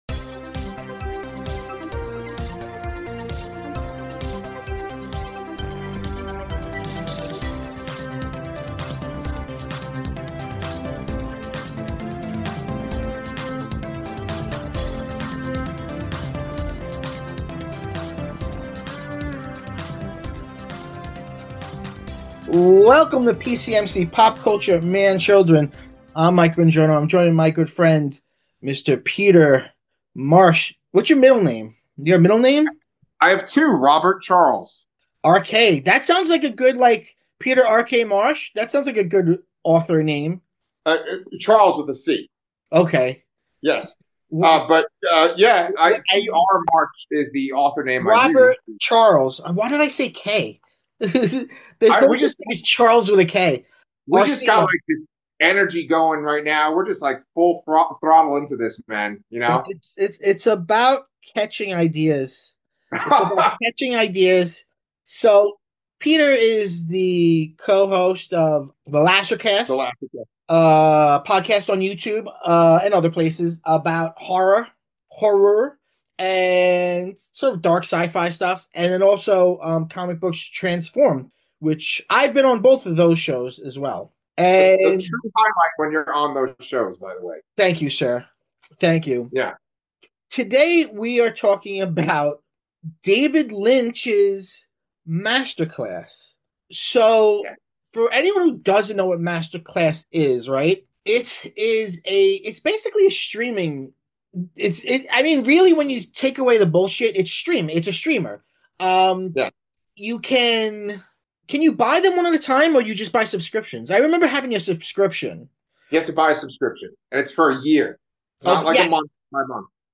This episode features a great conversation about the creative process and will entertain whether you're familiar with David Lynch or not!